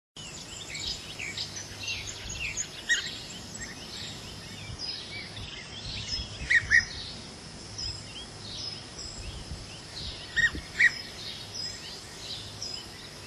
Sonido del Abejaruco comun.mp3